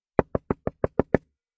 拟声词 " 敲 7
Tag: 敲门 木材 敲打 冲击